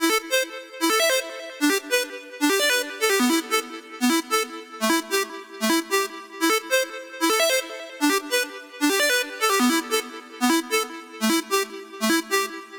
150BPM Lead 12 Emin.wav